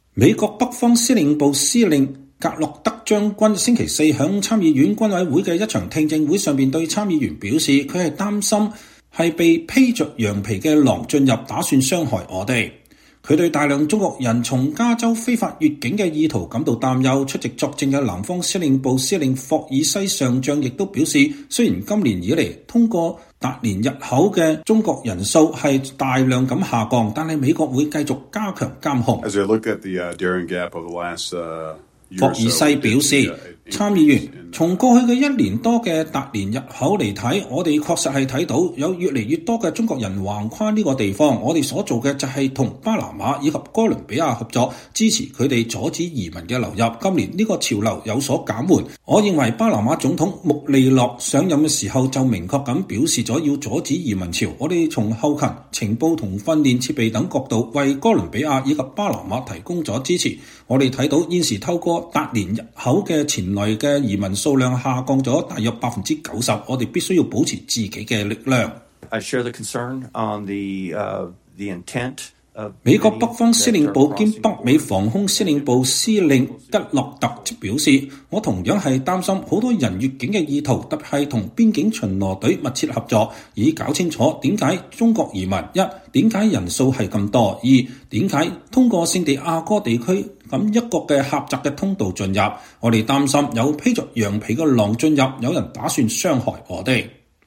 美國北方司令部司令吉洛特將軍(Gregory Guillot)週四在參議院軍委會的一場聽證會上對參議員表示，“我所擔心的是有披著羊皮的狼進入，打算傷害我們。”